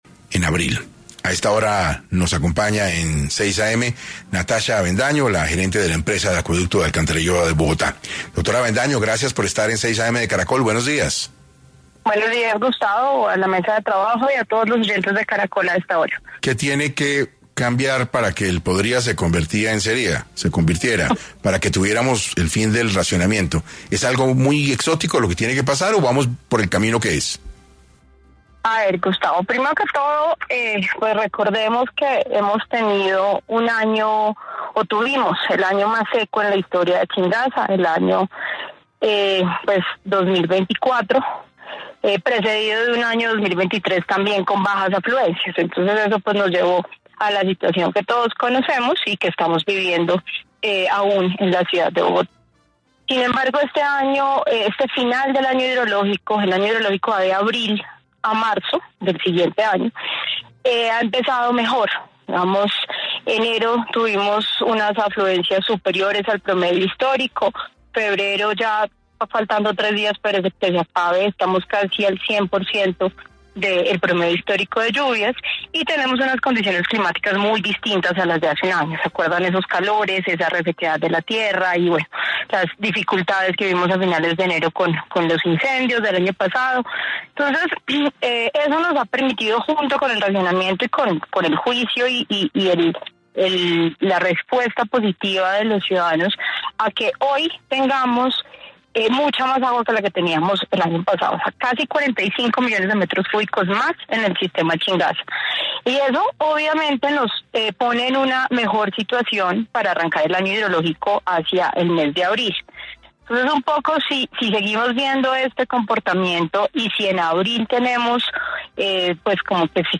En 6AM estuvo Natasha Avendaño, gerente de la Empresa de Acueducto y Alcantarillado de Bogotá, y aclaró cuándo finaliza el año hidrológico, fecha en la que podría acabar la medida de racionamiento en la capital